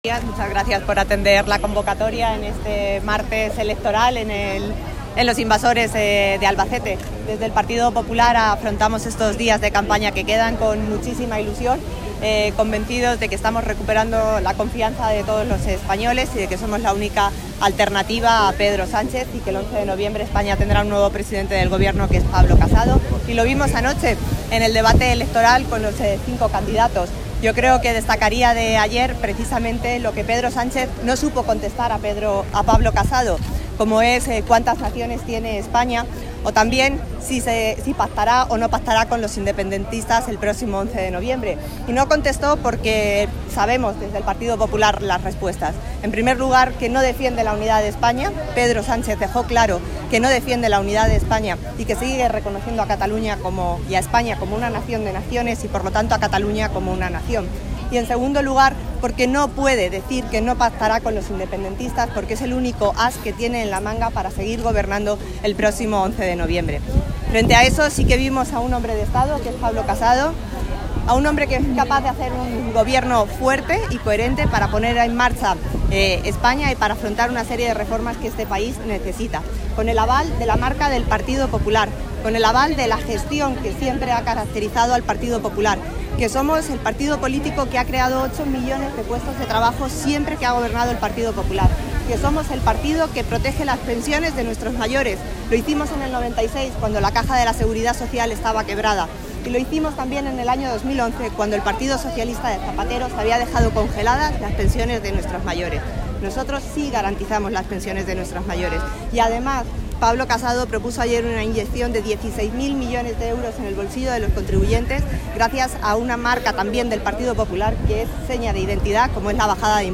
cortedevoz.carmennavarroenelmercado.m4a